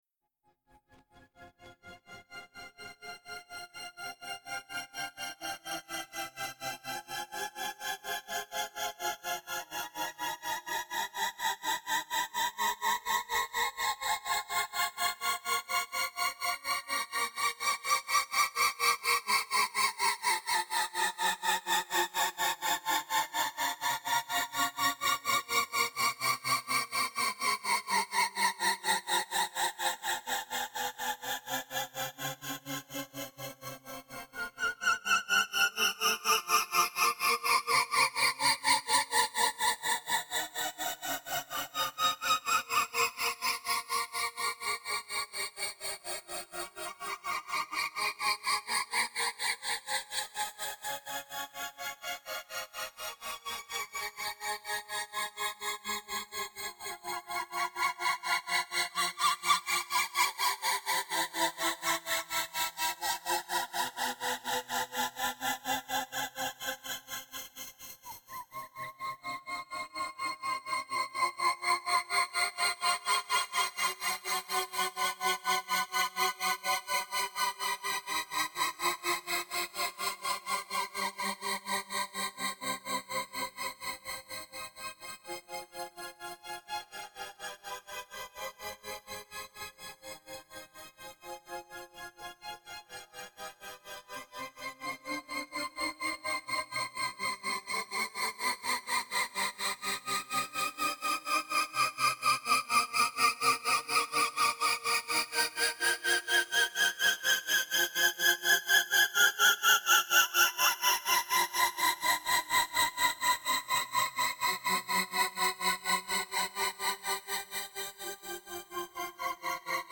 Then you just sing, and sing, over and over again. Till the harmonic form stratifies underneath, orchestrating the entire ritual crying.